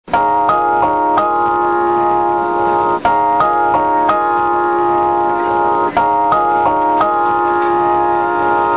※音質はテレコで収録し、なおかつ通勤時間帯(18時〜19時）に収録したためかなり悪く、音割れしております。
旧・１番線 発車メロディ− (43KB/8秒)